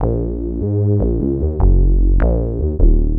44BASSLOOP-R.wav